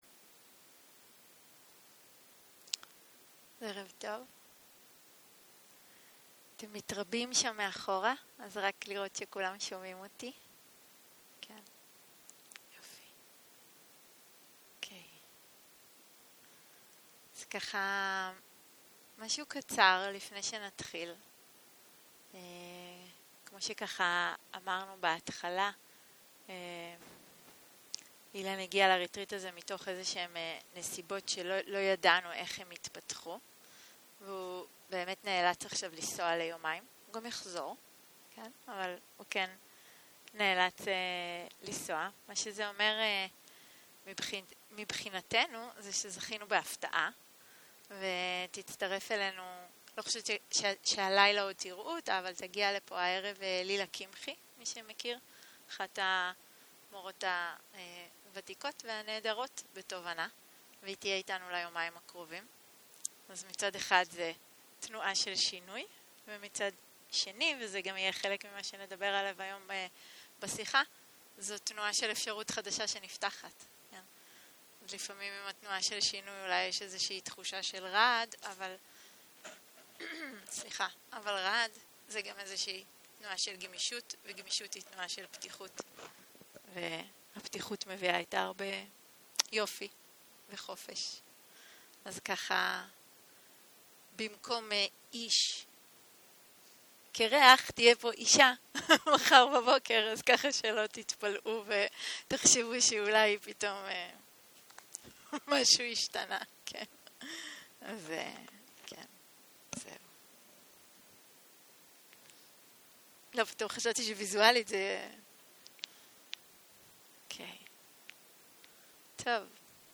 יום 2 - ערב - שיחת דהרמה - אימון התודעה - הקלטה 4